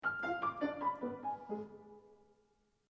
But this one is played in HALF the speed.